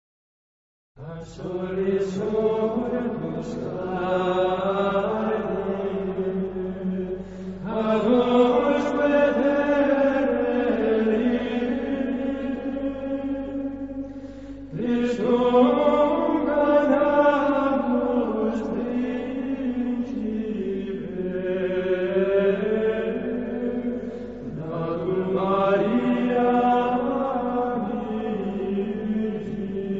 Himno (Modo 3.)